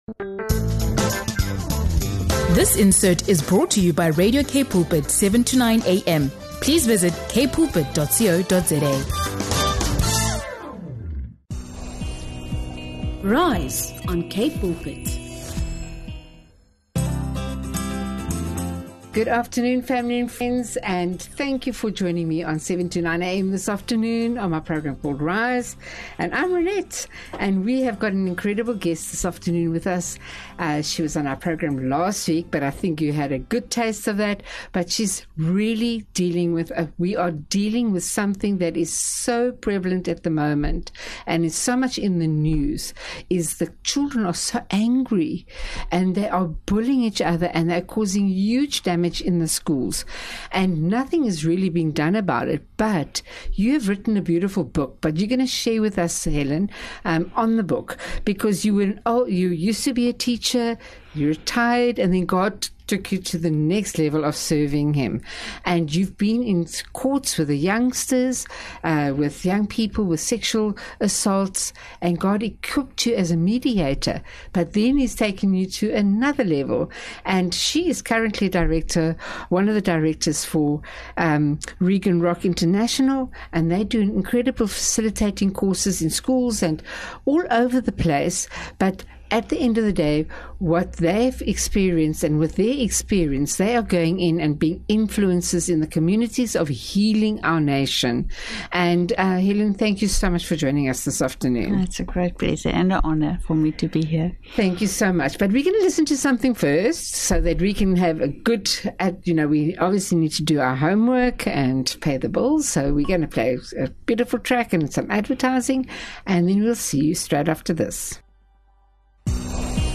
In this inspiring conversation